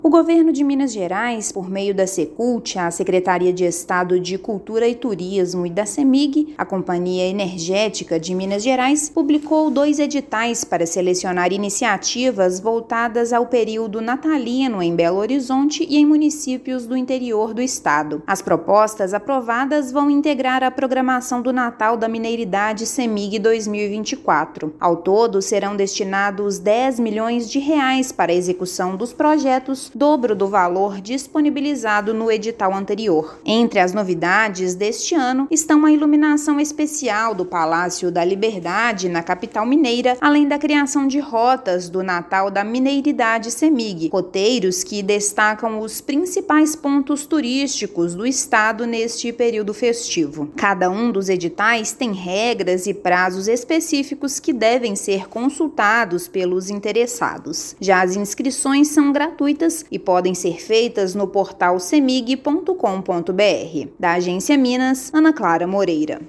Companhia vai selecionar projetos com temáticas natalinas na capital e no interior do estado; incentivo cultural é de R$ 10 milhões. Ouça matéria de rádio.